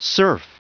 Prononciation du mot serf en anglais (fichier audio)
Prononciation du mot : serf